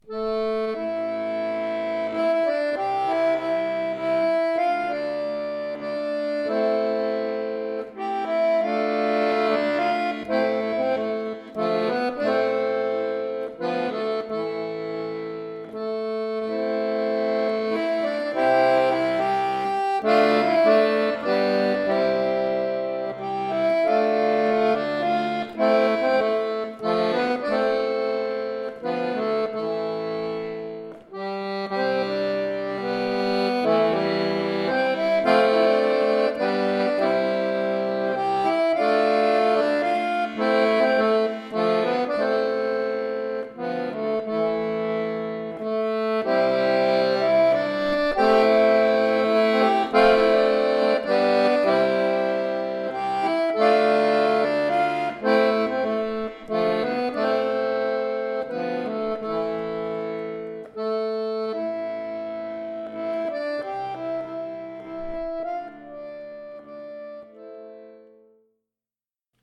Folksong